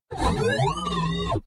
惊讶的哔哔声